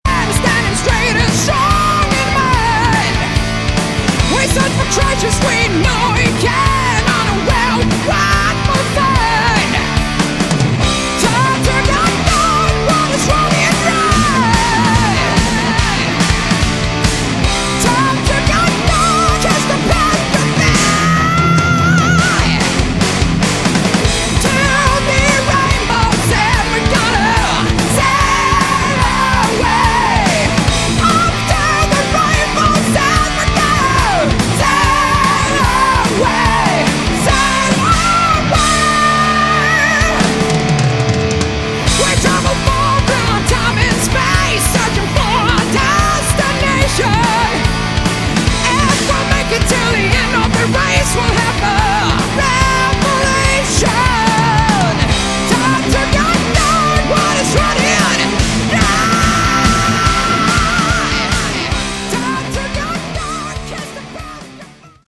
Category: Hard Rock
Vocals
Guitars
Drums
Bass